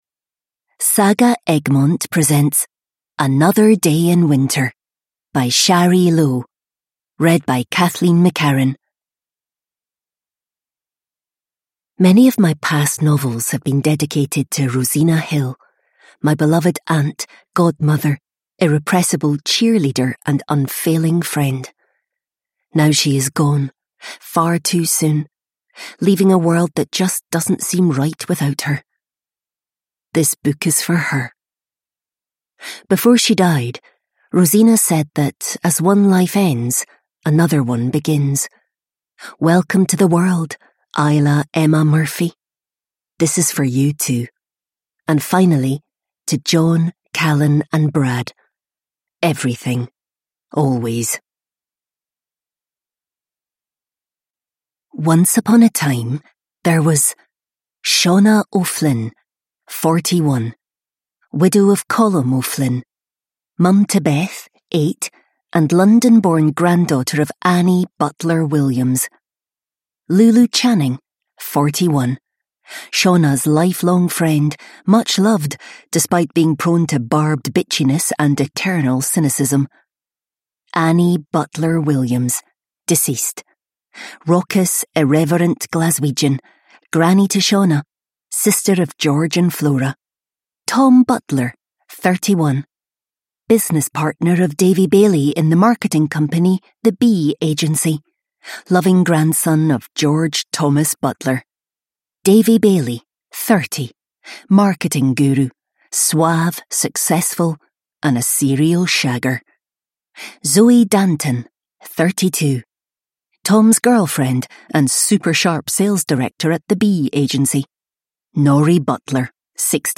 Another Day in Winter: An emotional, heart-warming read to curl up with – Ljudbok